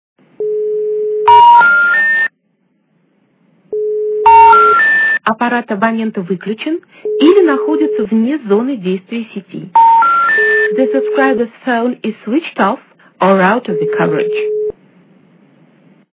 » Звуки » Люди фразы » Женский голос - Абонент не зоне
При прослушивании Женский голос - Абонент не зоне качество понижено и присутствуют гудки.
Звук Женский голос - Абонент не зоне